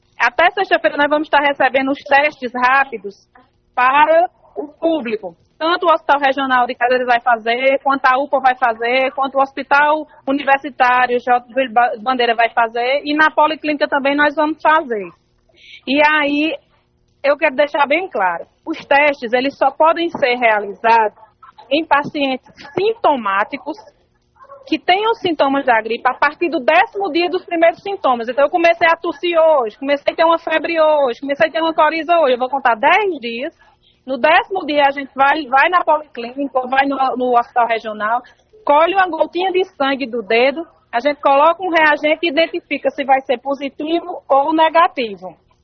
Em Cajazeiras a secretária de Saúde e presidente do Covid – 19 em Cajazeiras, francimones Rolim, afirmou a reportagem do rádio vivo que os teste rápidos serão utilizados nas pessoas que apresentam síndrome gripal como febre, dor de garganta.